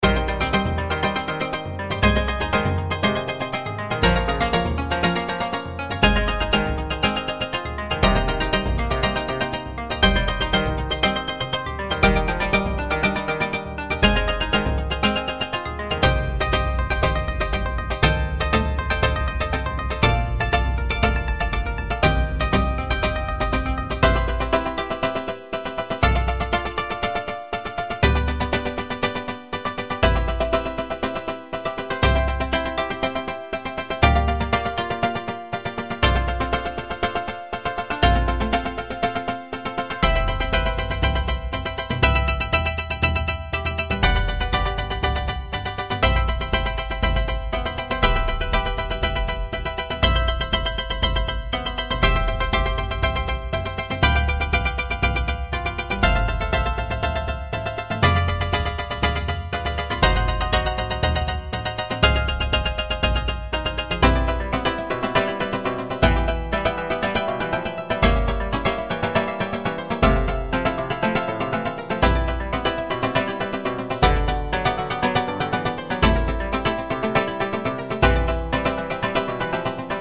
琴、三味線、太鼓 ※和楽器